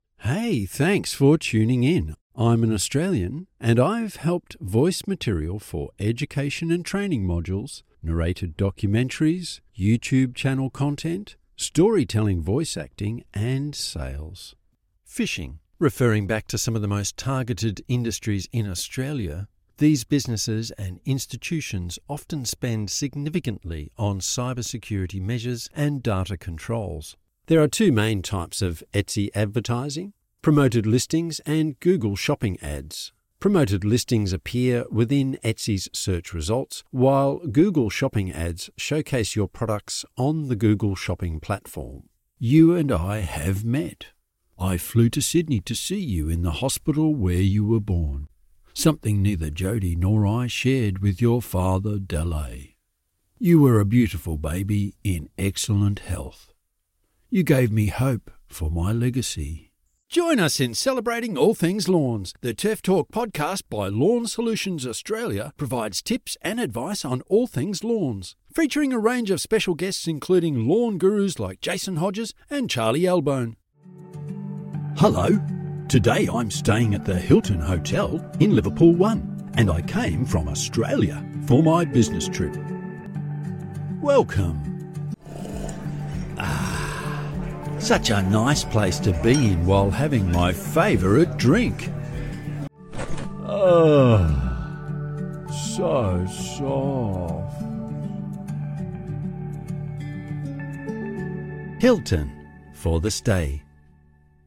Never any Artificial Voices used, unlike other sites. All our voice actors are premium seasoned professionals.
Adult (30-50) | Older Sound (50+)